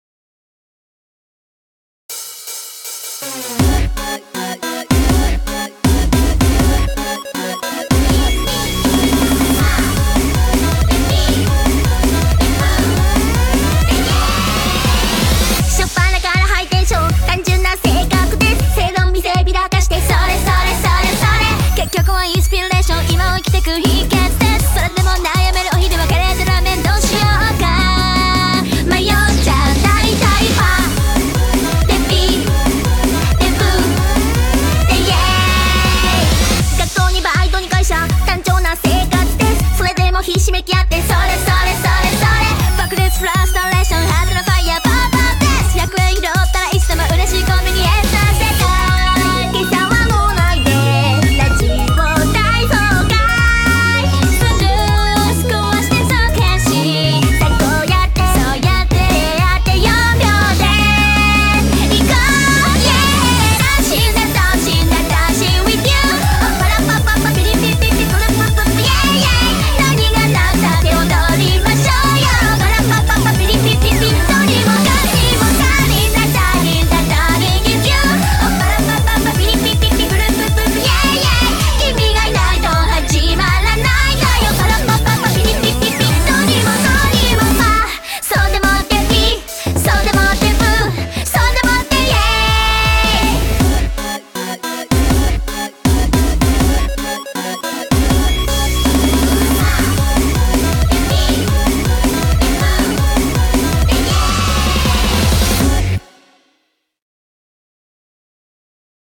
BPM160
Audio QualityLine Out